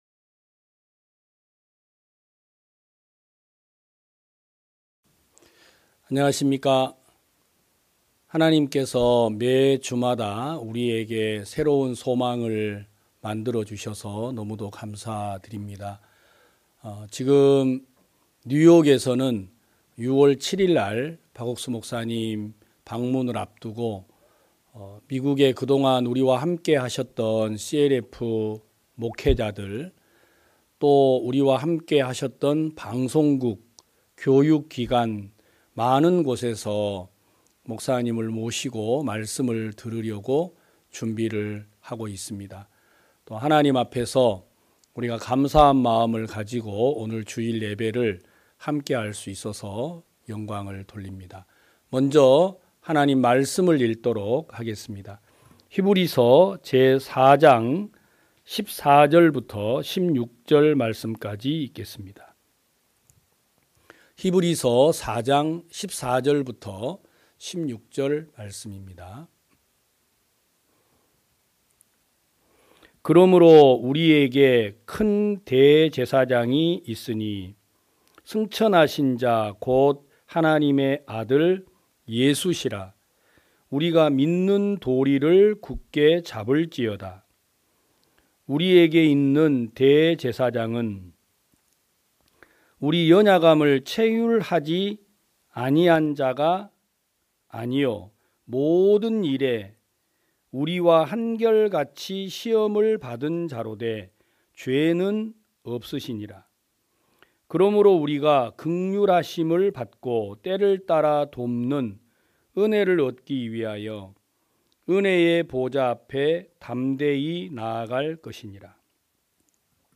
2021년 05월 30일 기쁜소식부산대연교회 주일오전예배
성도들이 모두 교회에 모여 말씀을 듣는 주일 예배의 설교는, 한 주간 우리 마음을 채웠던 생각을 내려두고 하나님의 말씀으로 가득 채우는 시간입니다.